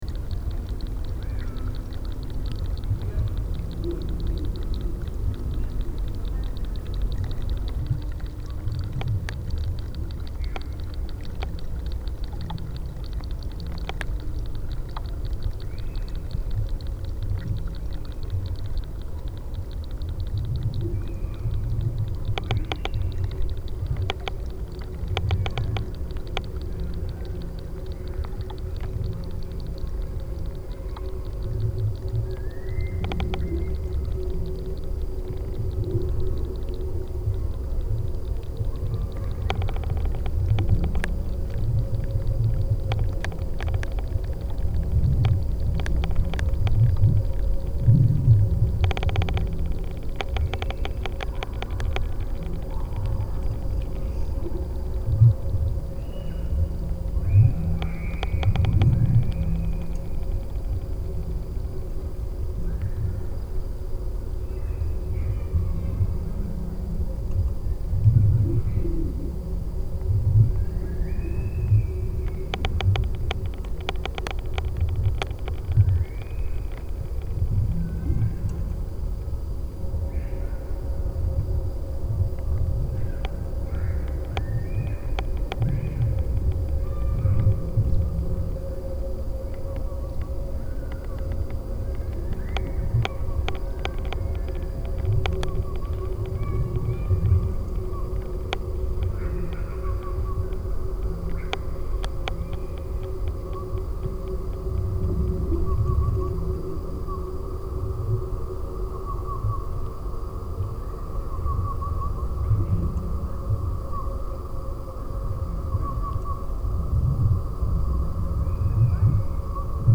Field Recording Series